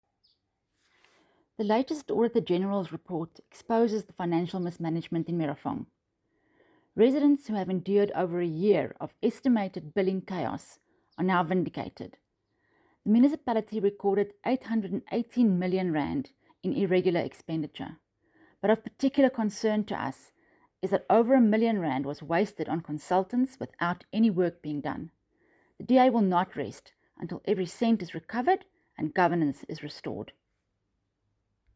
Afrikaans soundbites attached by Ina Cilliers MPL.